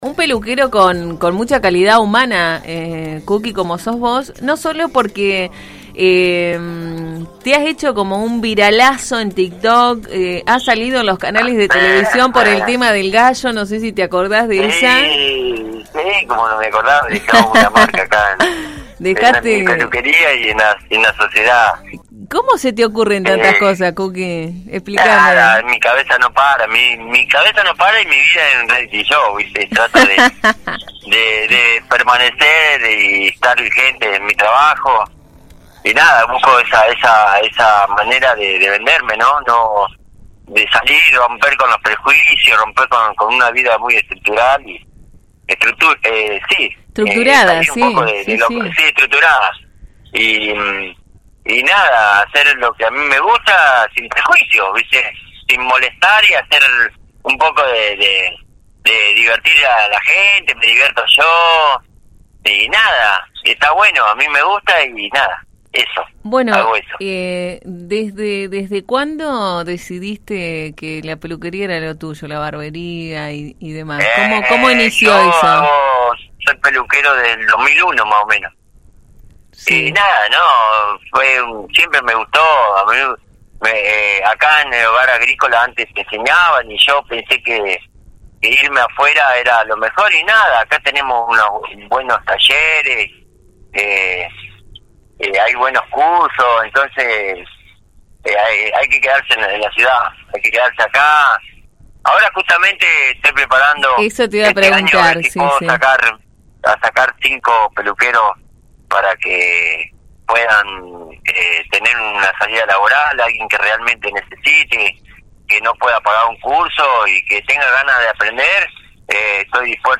El querido estilista habló con la 91.5 sobre su actividad diaria en el Día del Peluquero.